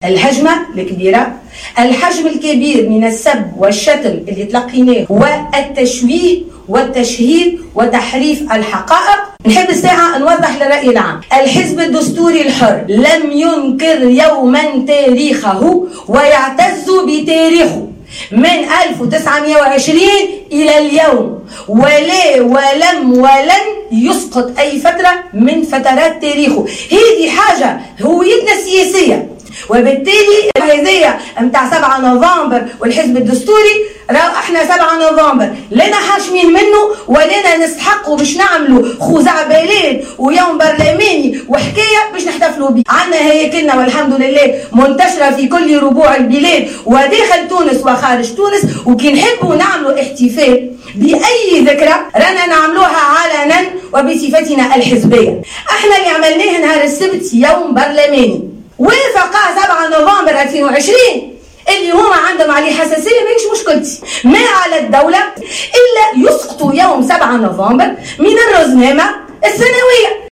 وشدّدت موسي خلال نقطة إعلامية نظمها الحزب اليوم بالبرلمان ، على أن الدستوري الحر لم ينكر يوما تاريخه ولا ولم ولن يسقط أية فترة من فترات تاريخه أو من هويته السياسية.